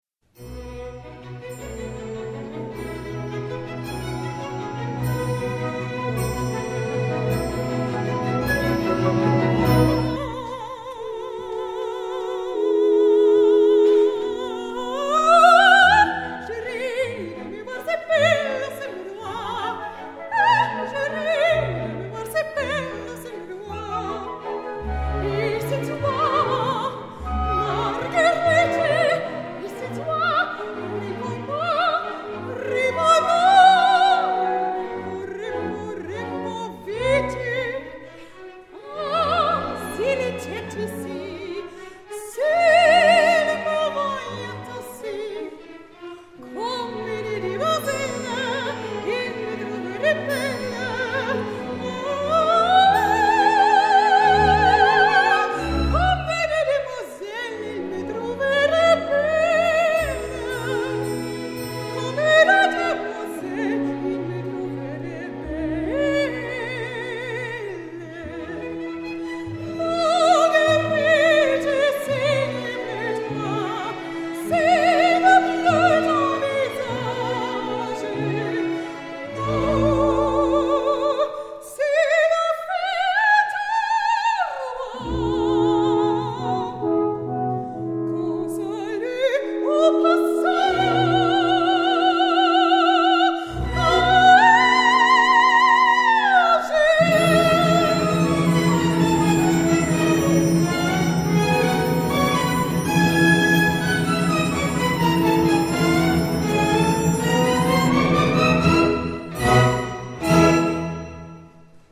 Sopran
»Juwelenarie« aus Faust, Charles Gounod (Ausschnitt) Mit Jugendorchester Baden-Baden